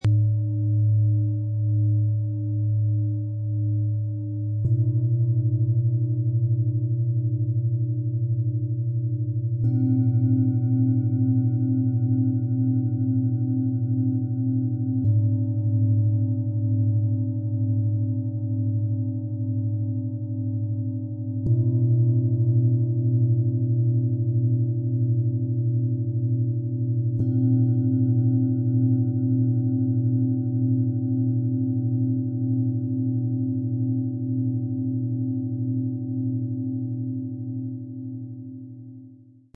Tiefer Halt - Starke Mitte - Weite im Kopf - Klangmassage Set aus 3 Klangschalen, Ø 16,7 - 25,2 cm, 3,58 kg
Mit dem Sound-Player - Jetzt reinhören lässt sich der Originalklang genau dieser Schalen anhören – von tief und tragend bis zu klar und öffnend.
Ihr tiefer Ton breitet sich sanft und kraftvoll aus und unterstützt dabei, Ballast loszulassen und Ruhe zu finden.
Sie wirkt warm und voll.
Ihr klarer, singender Klang öffnet den oberen Körperbereich und schließt die Anwendung mit Weite und Klarheit ab.
Bengalen Schale, Glänzend, 25,2 cm Durchmesser, 10,7 cm Höhe